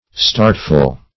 startful - definition of startful - synonyms, pronunciation, spelling from Free Dictionary
Startful \Start"ful\ (st[aum]rt"f[.u]l)